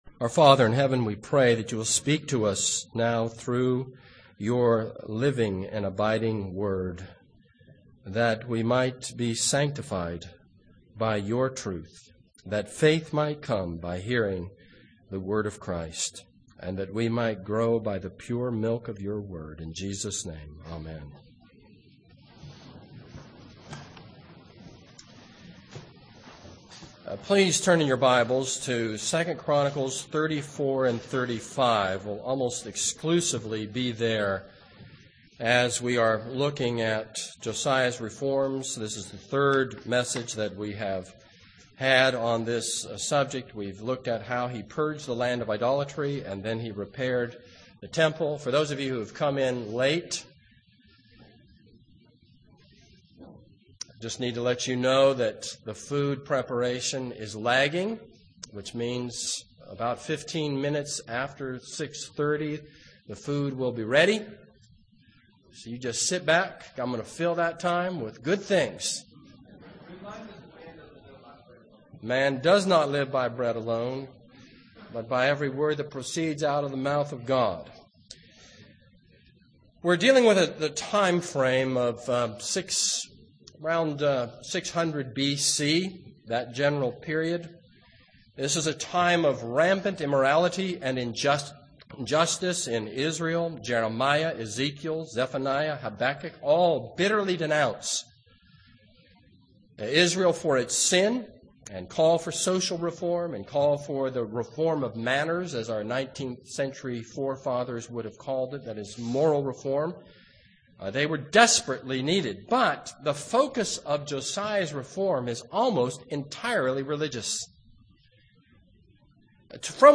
This is a sermon on 2 Kings 22-23:30.